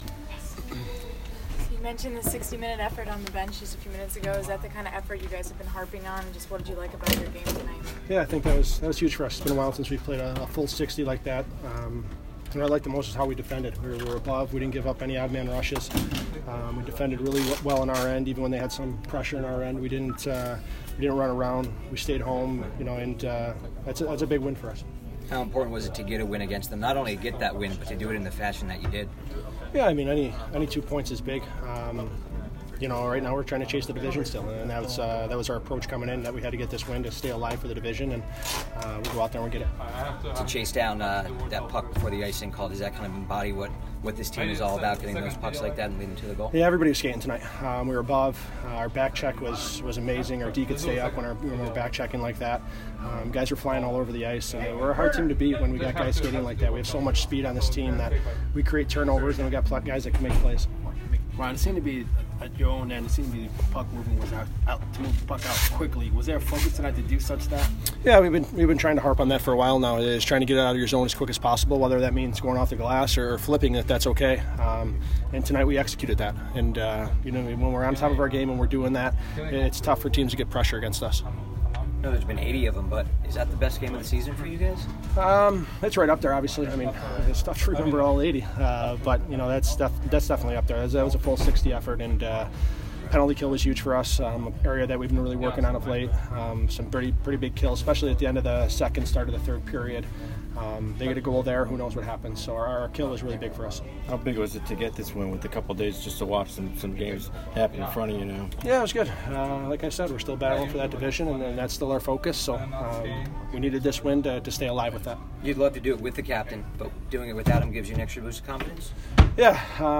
Ryan Callahan post-game 4/3